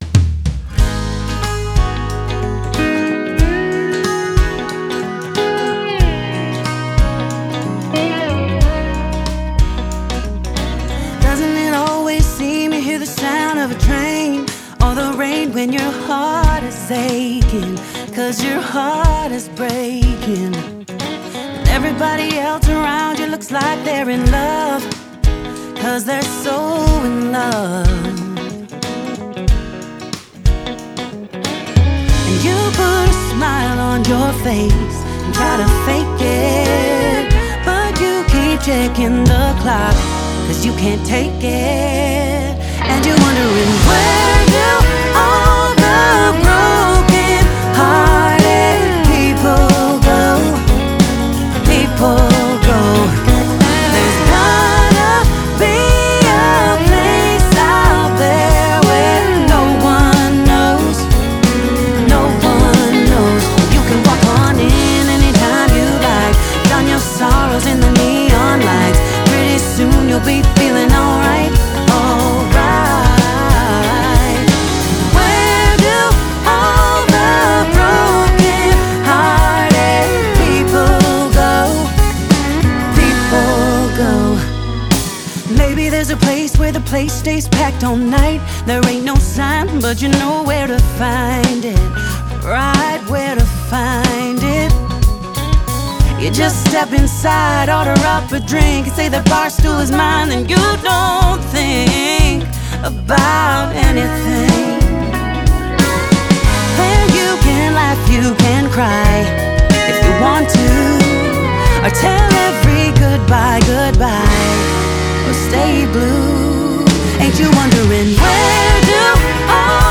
Southern Roots inspired country duo
rich, emotive vocals
evocative slide guitar